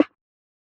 SNARE III.wav